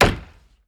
Chopping wood 2.wav